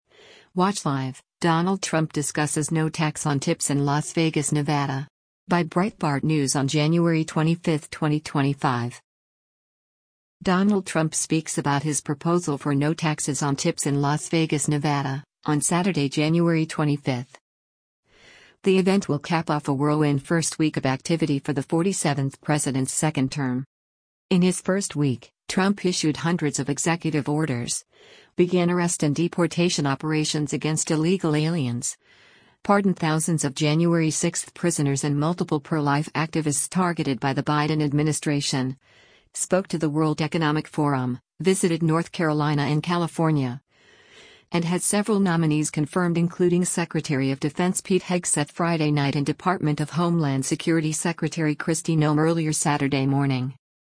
Donald Trump speaks about his proposal for no taxes on tips in Las Vegas, Nevada, on Saturday, January 25.